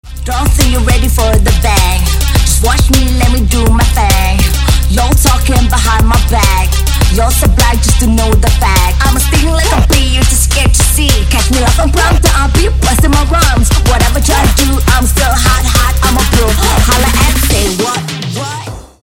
• Качество: 320, Stereo
женский вокал
Хип-хоп
Trap
twerk
Rap
Bass